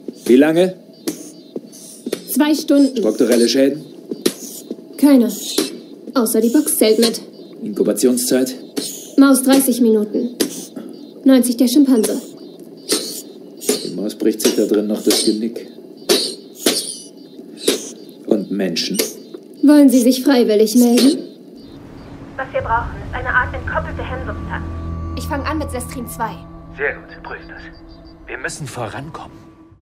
Sprecherin, Synchronsprecherin